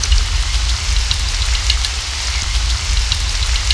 50 RAIN   -L.wav